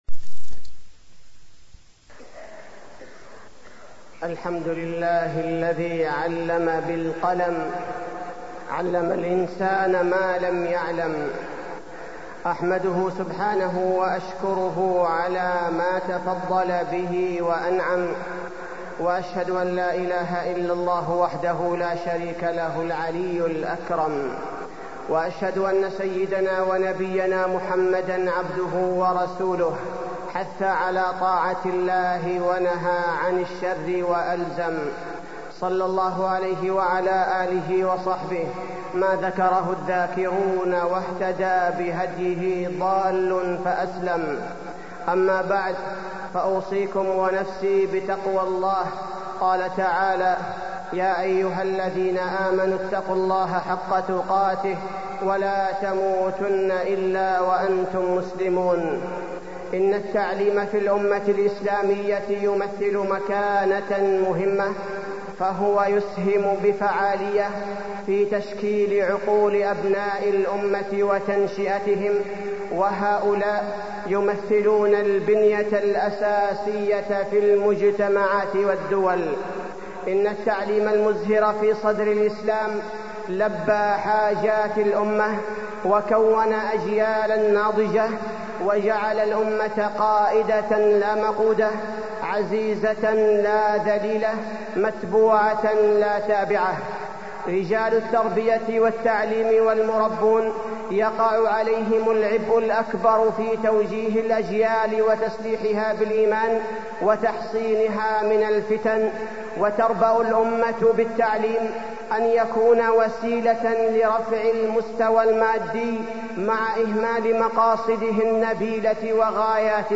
تاريخ النشر ٦ رجب ١٤٢٣ هـ المكان: المسجد النبوي الشيخ: فضيلة الشيخ عبدالباري الثبيتي فضيلة الشيخ عبدالباري الثبيتي التعليم والمربون